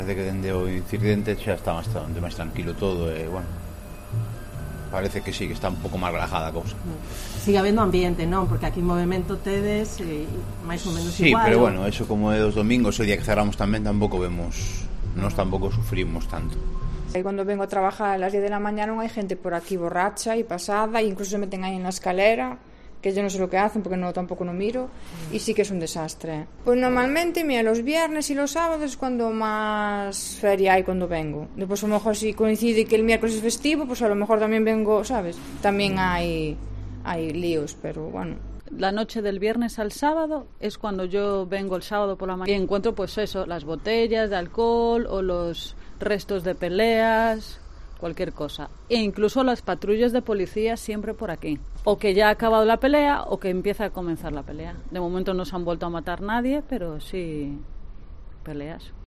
Vecinos y comerciantes de la calle Santiago de Chile repasan la situación un año después